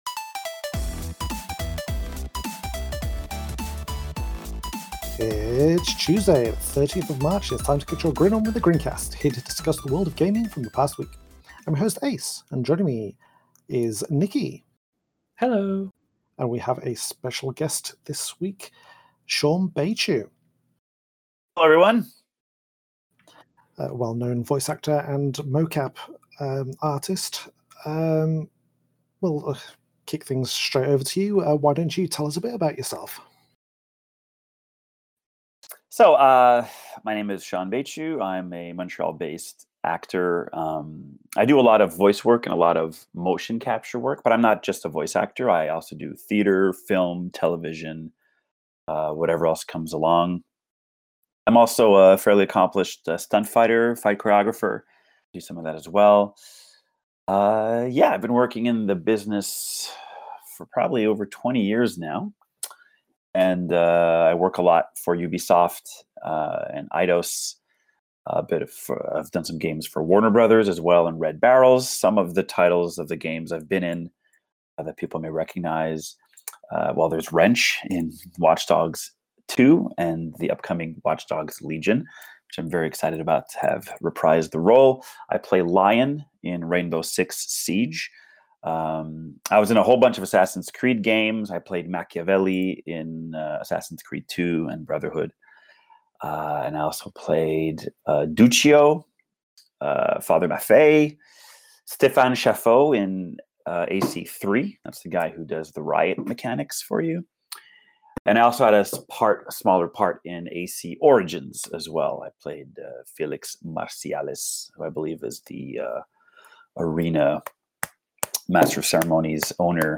This week the gang talked about: